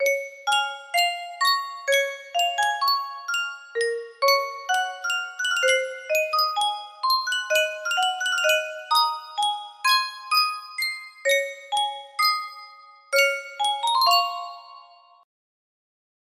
Yunsheng Music Box - You Made Me Love You 5365 music box melody
Full range 60